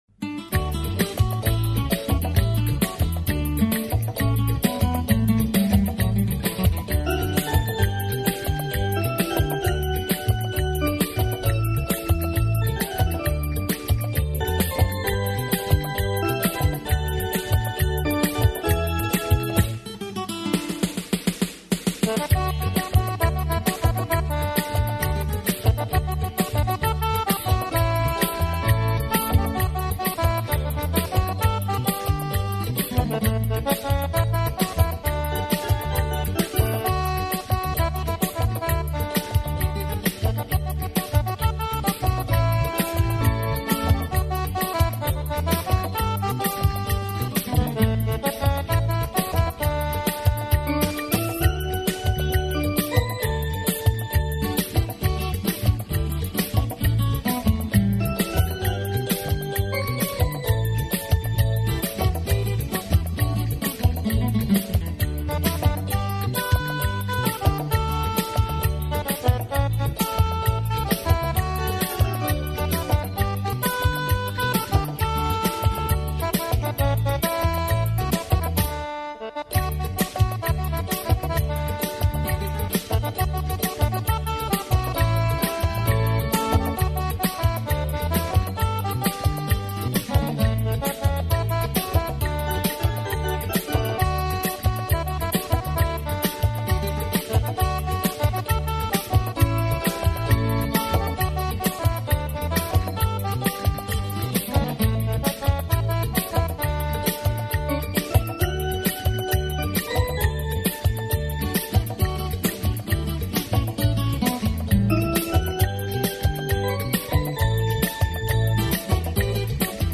Sinhala Instrumental Mp3 Download